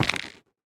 minecraft / sounds / block / stem / step3.ogg
step3.ogg